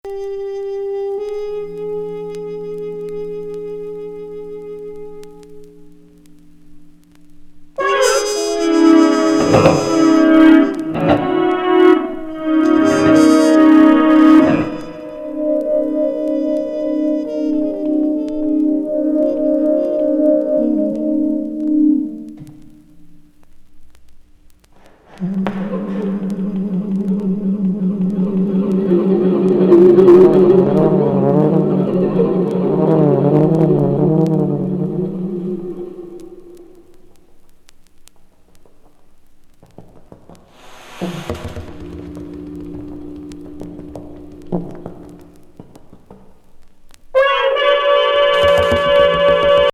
傑作フリージャズ85年NY録音!様々なエフェクトによって幻想的な音色を響か
せるエレクトリック・ギターに、トロンボーンなどが混じるトリオ作!